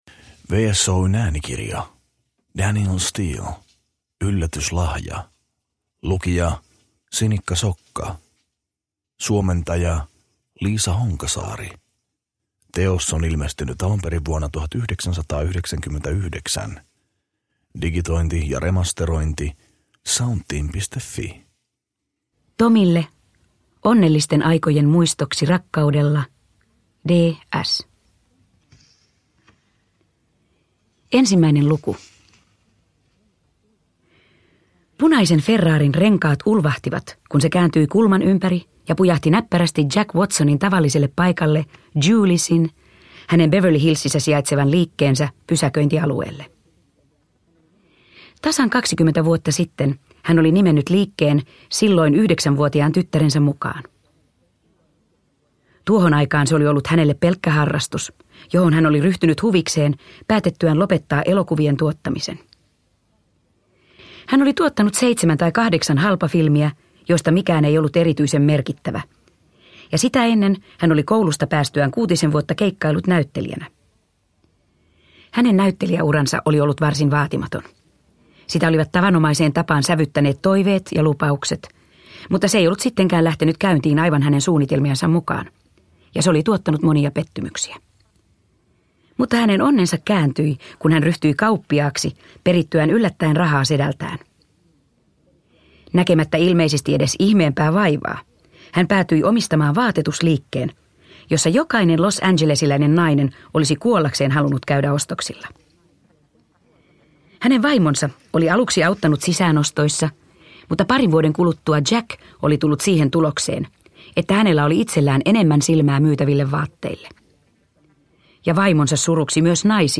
Yllätyslahja (ljudbok) av Danielle Steel